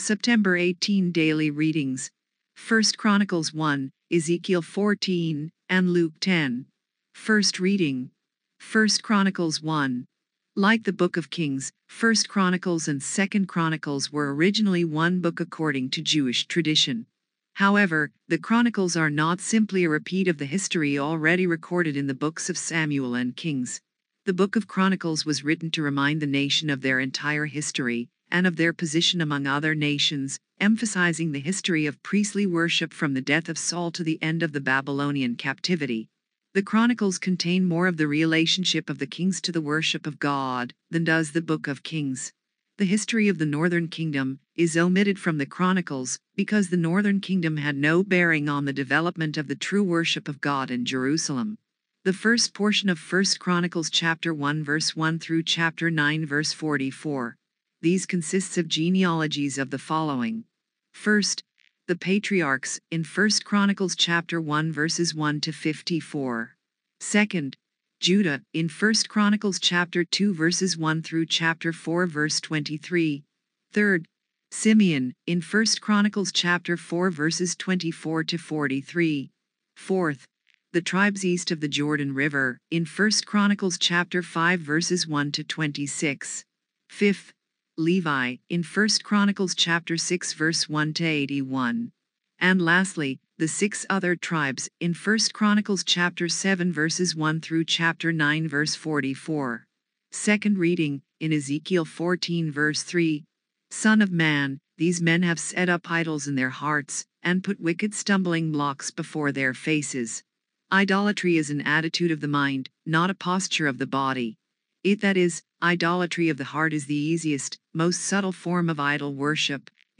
Reading 1 - 1Ch 1Like the Book of Kings, 1Ch and 2Ch were originally one book according to Jewish tradition.